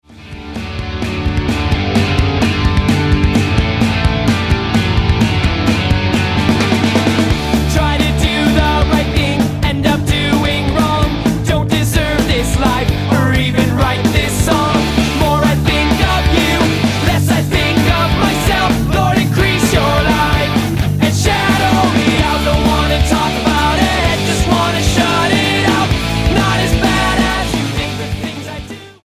Hawaiian punk
Style: Hard Music